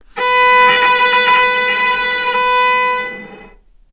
healing-soundscapes/Sound Banks/HSS_OP_Pack/Strings/cello/sul-ponticello/vc_sp-B4-ff.AIF at 01ef1558cb71fd5ac0c09b723e26d76a8e1b755c
vc_sp-B4-ff.AIF